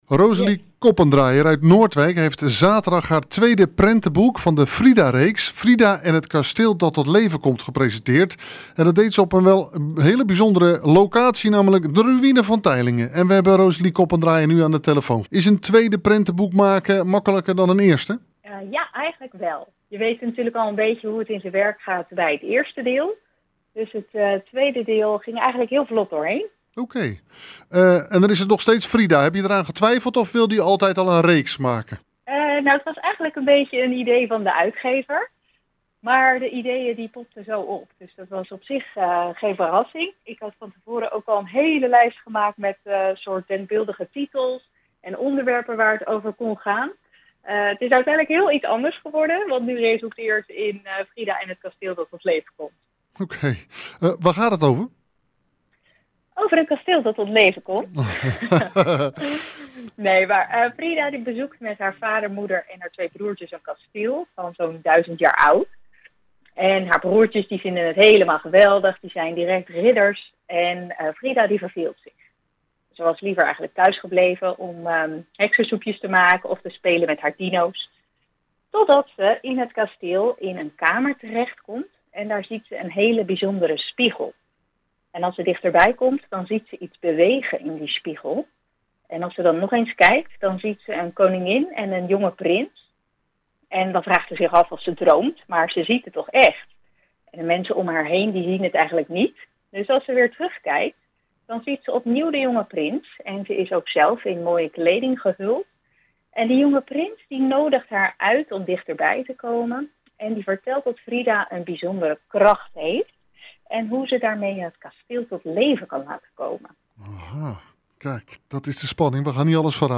Hieronder het radio-interview: